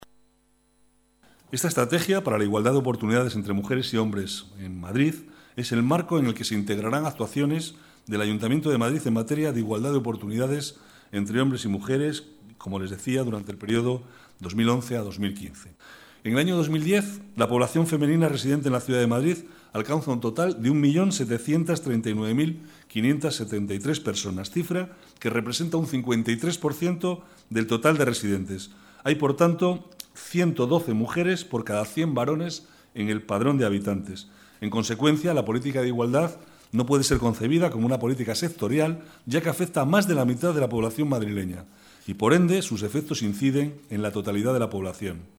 Nueva ventana:Declaraciones del vicealcalde, Manuel Cobo: Aprobada la Estrategia para la Igualdad de Oportunidades 2011-2015